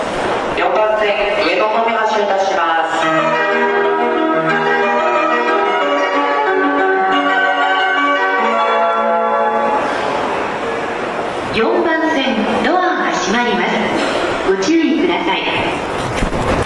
日本の玄関口ですが、メロディーは特に変わっているものでもありません。
発車5音色c --